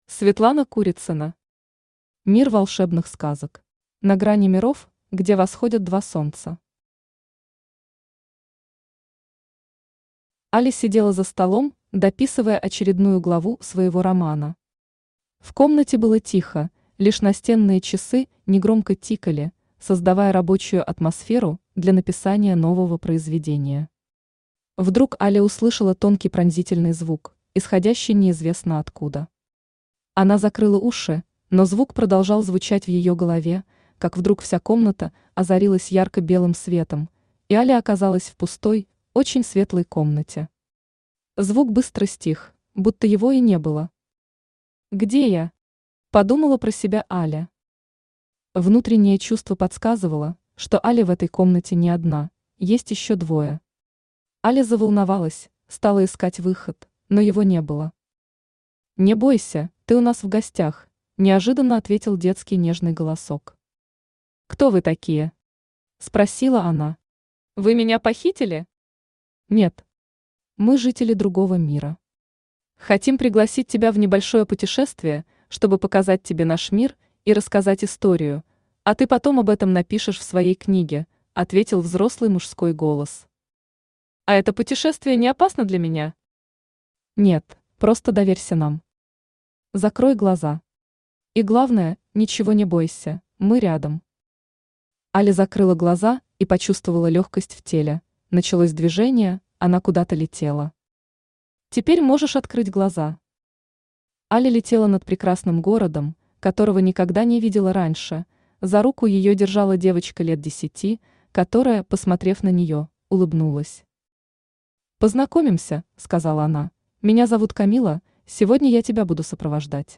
Аудиокнига Мир волшебных сказок | Библиотека аудиокниг
Aудиокнига Мир волшебных сказок Автор Светлана Вячеславовна Курицына Читает аудиокнигу Авточтец ЛитРес.